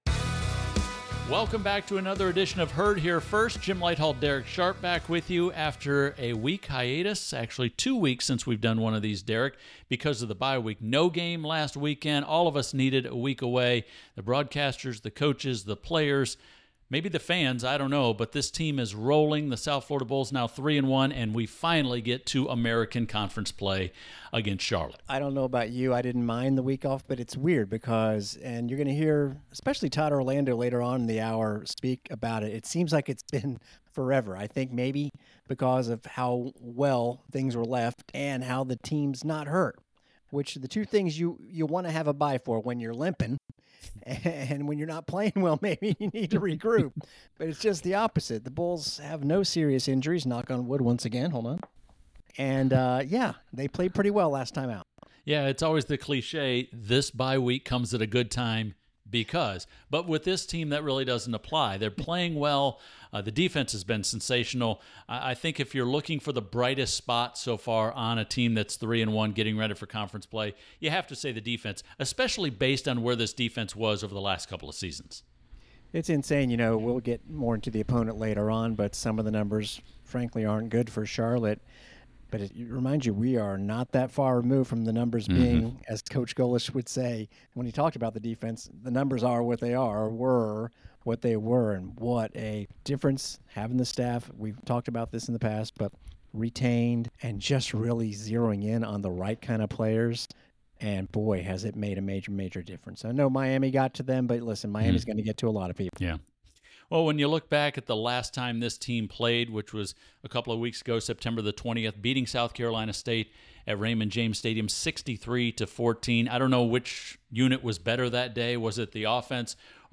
Herd Here First - Hour 1 Radio Pregame USF vs. Charlotte